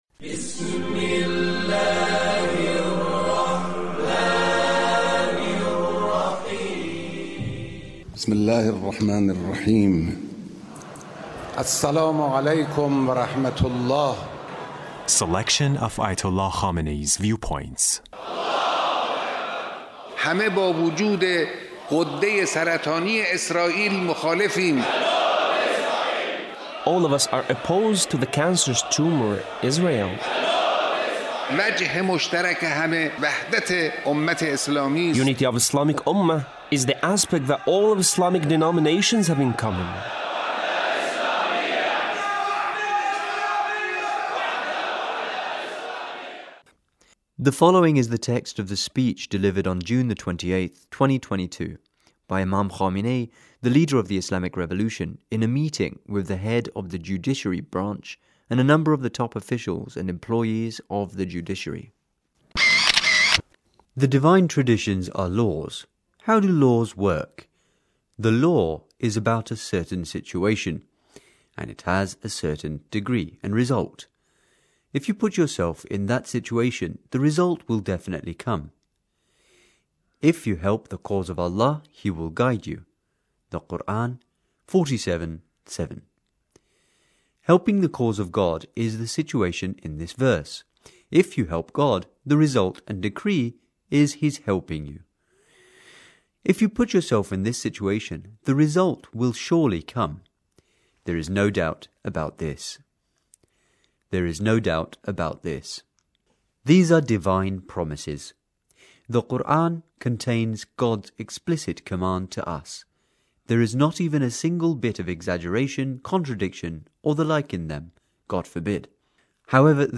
The Leader's speech in a meeting with a number of the top officials and employees of the Judiciary.